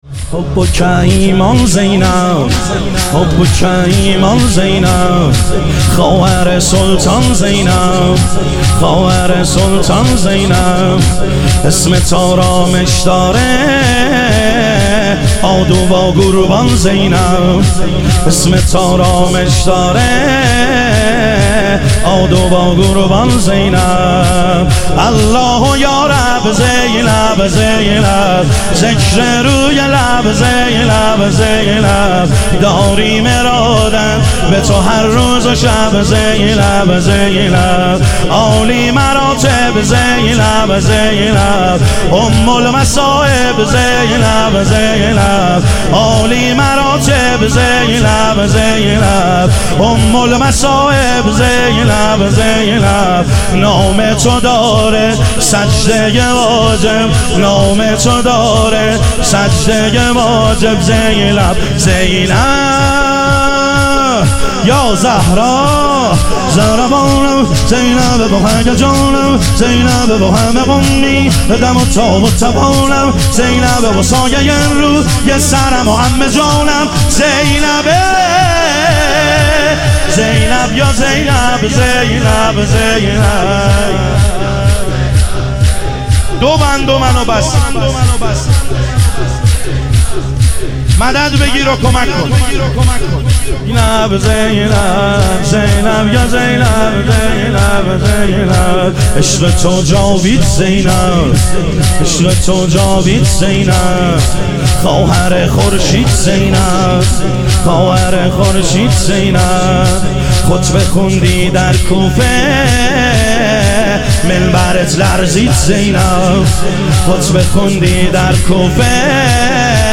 محرم الحرام - شور